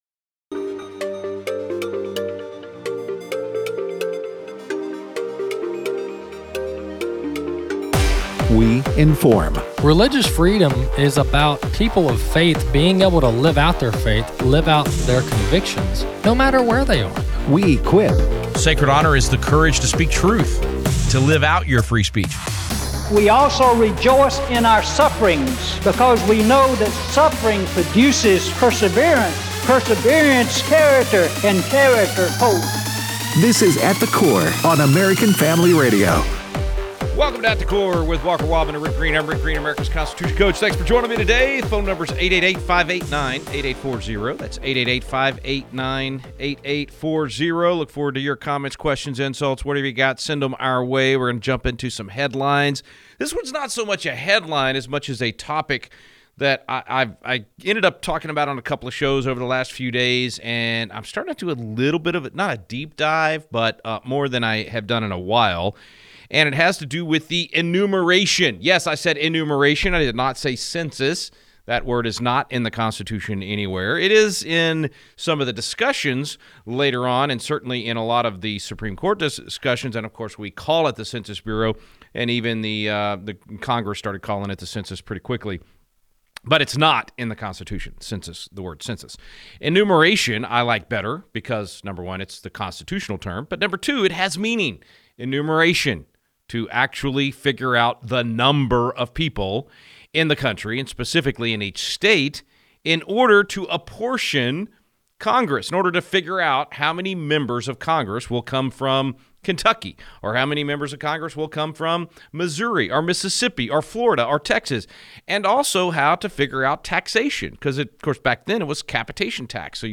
Callers continue to weigh in on their census interaction